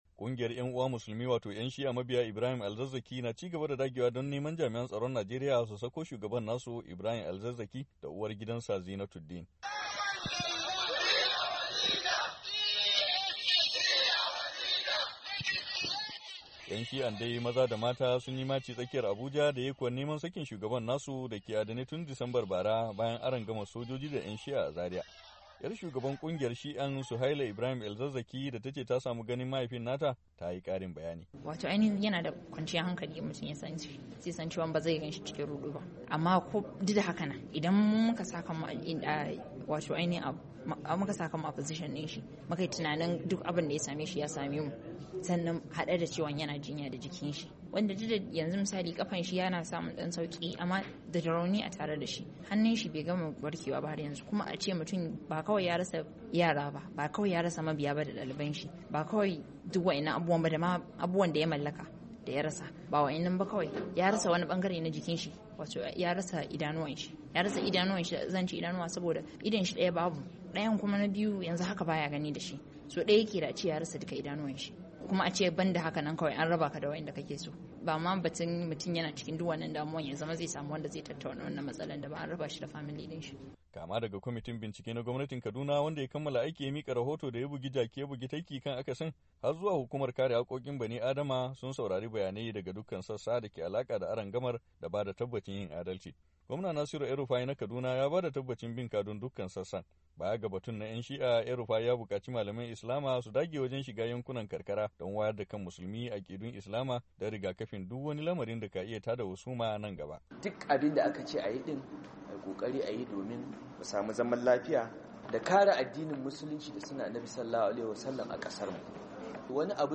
Labari da Dumi-Duminsa